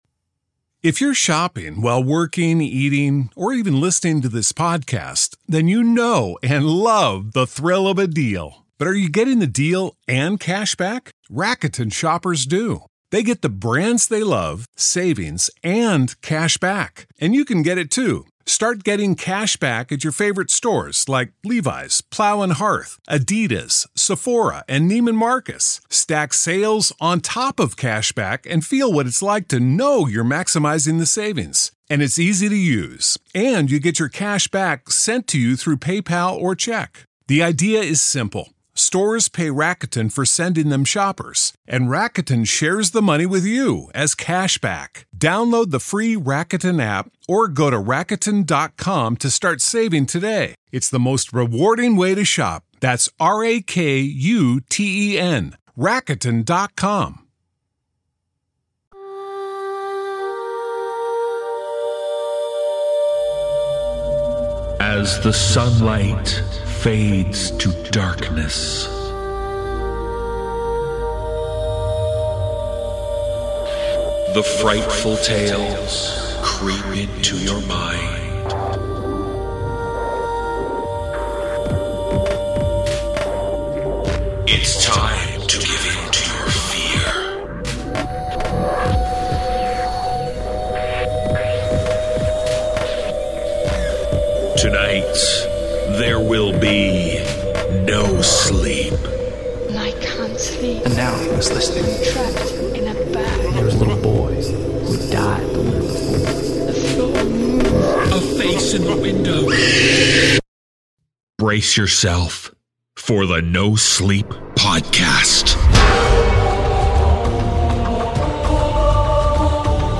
The free version features only the first tale.